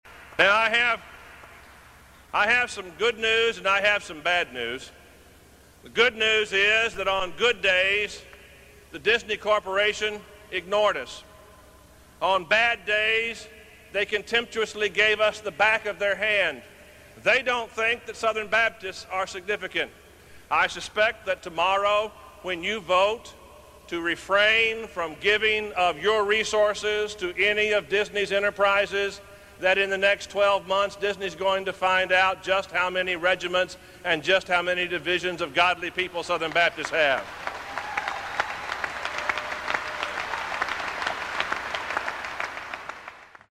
It is helpful to get a feel for the climate of the 1997 SBC meeting. Let’s listen to a portion of the discussion that took place before the SBC voted on the resolution.